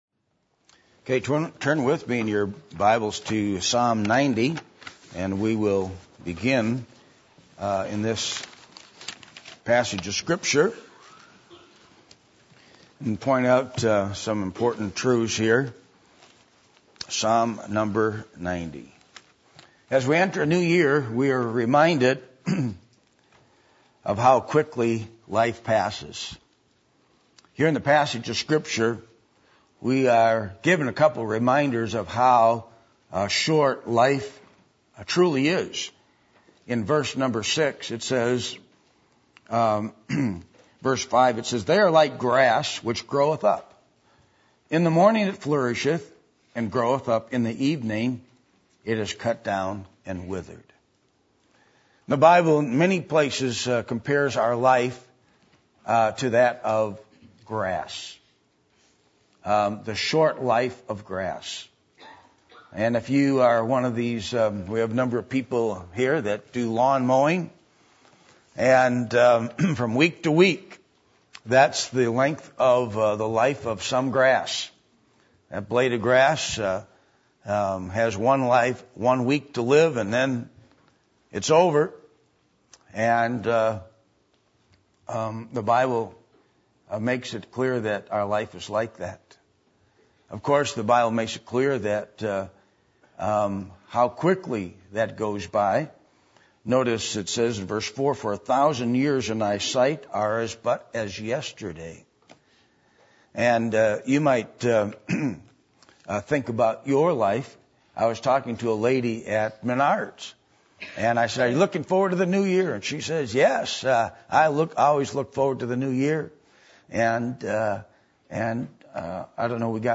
Passage: Psalm 90:1-17 Service Type: Sunday Morning %todo_render% « Watch Night Service